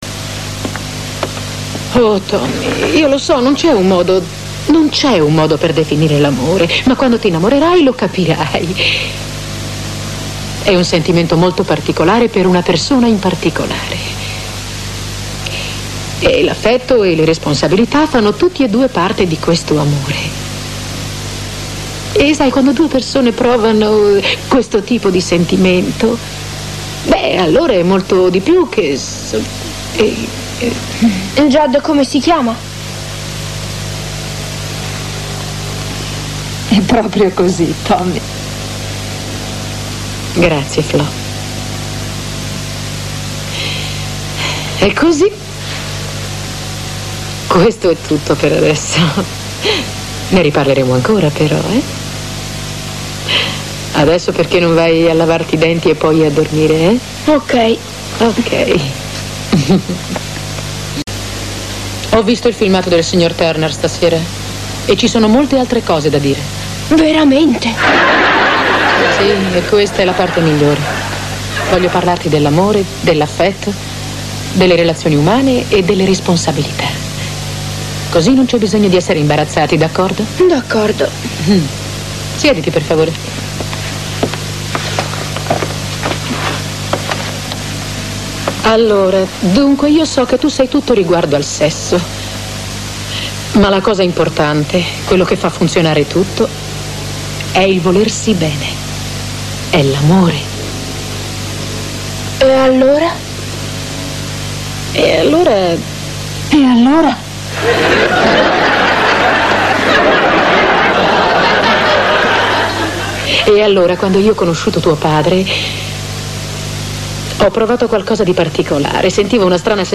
telefilm "Alice", in cui doppia Linda Lavin.